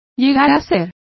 Complete with pronunciation of the translation of become.